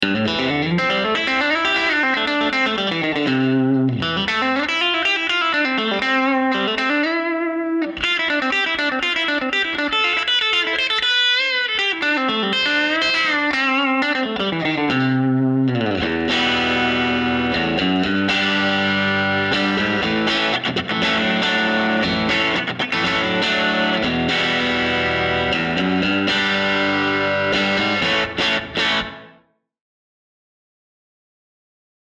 This Vintage Tele lead pickup uses alnico 2 rod magnets to provide a softer treble attack for players who want Telecaster tone without excess bite.
APTL-1_CRUNCH_SOLO_SM